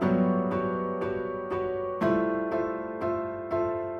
Index of /musicradar/gangster-sting-samples/120bpm Loops
GS_Piano_120-G1.wav